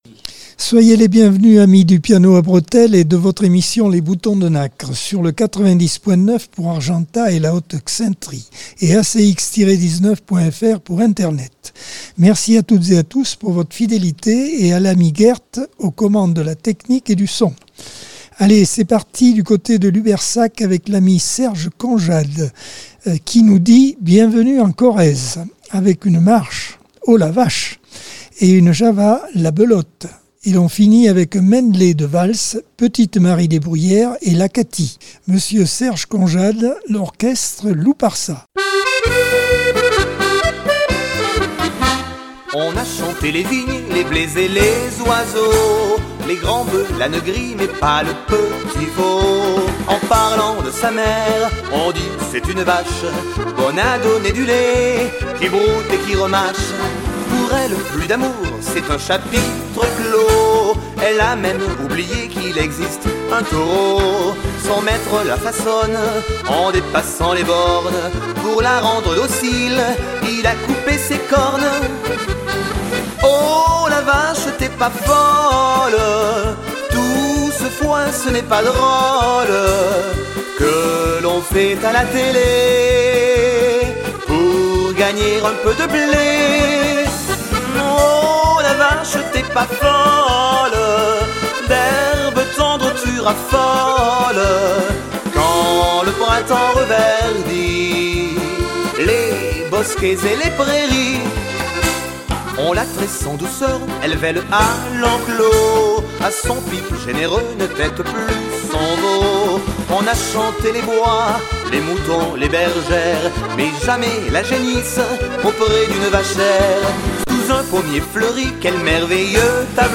Accordeon 2024 sem 33 bloc 1 - Radio ACX